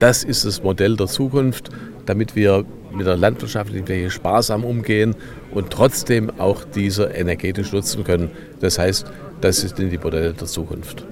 Peter Hauk, Baden-Württembergs Landwirtschaftsminister (CDU)